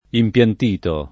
impiantito [ imp L ant & to ]